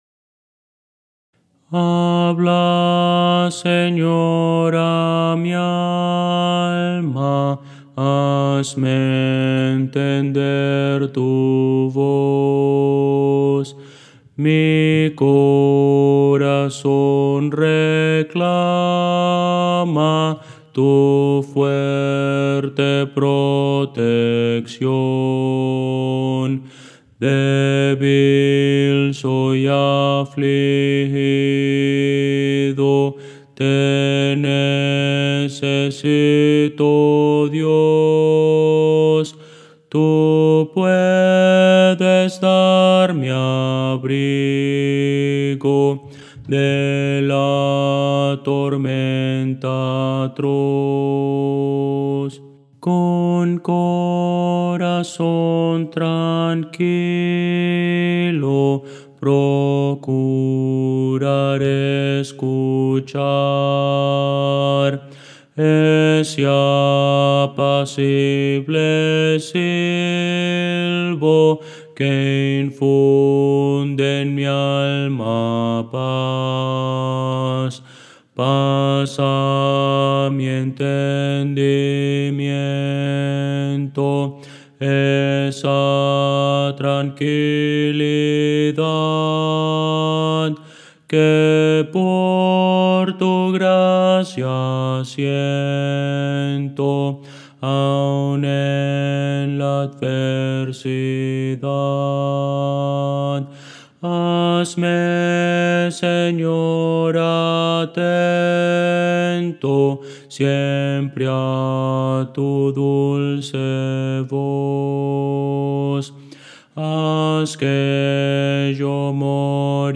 Música: MIDI
Voces para coro